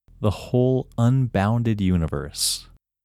WHOLENESS English Male 14
WHOLENESS-English-Male-14.mp3